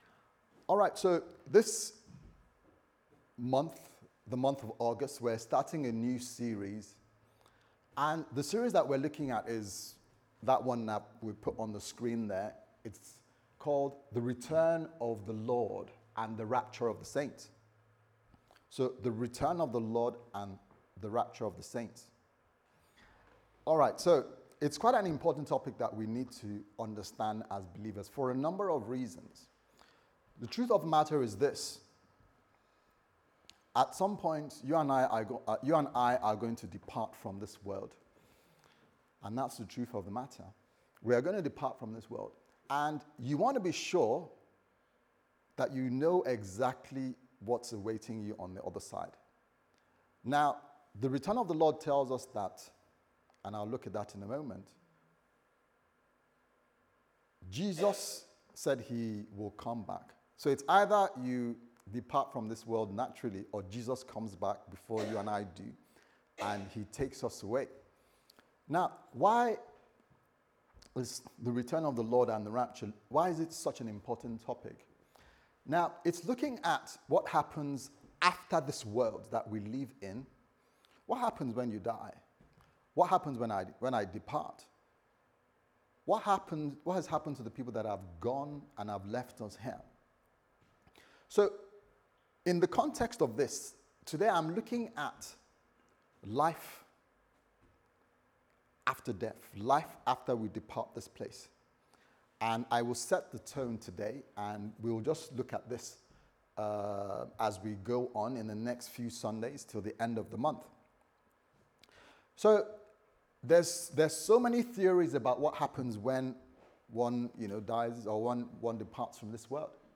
Service Type: Sunday Service Sermon